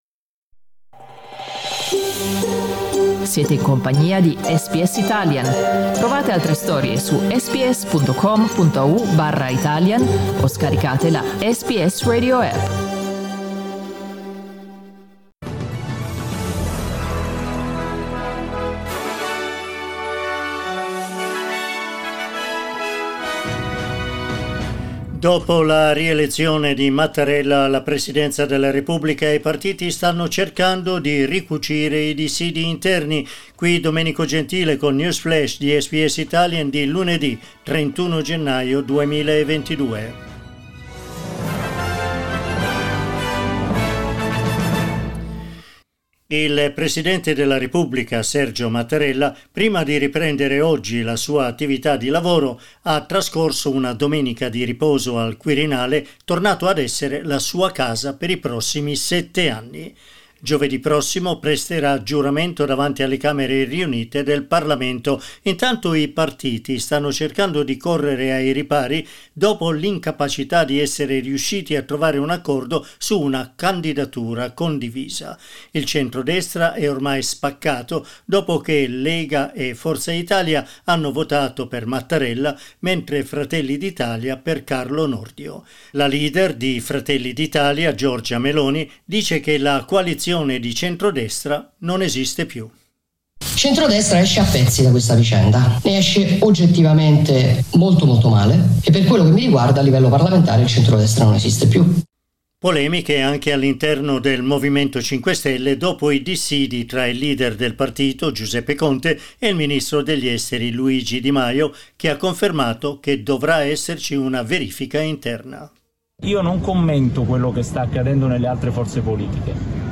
News flash lunedì 31 gennaio 2022